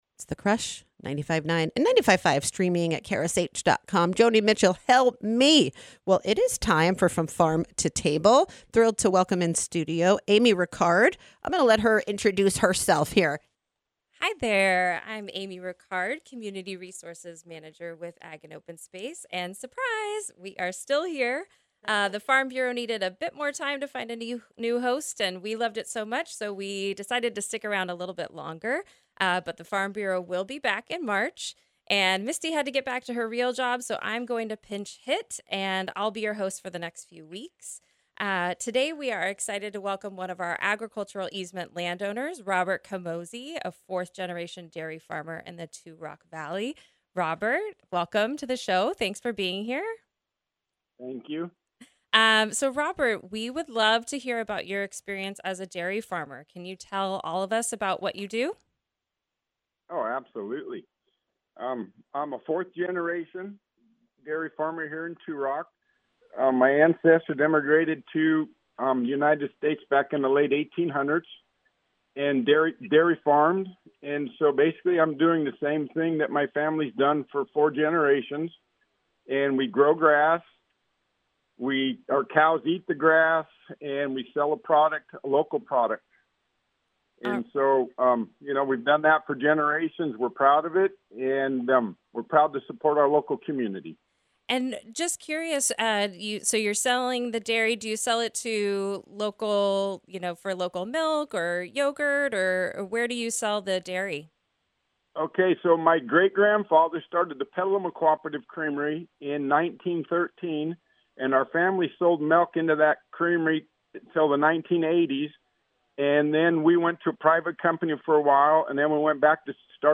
Catch recordings of the From Farm to Table radio show for conversations with Ag + Open Space about land stewardship and conservation!
Interview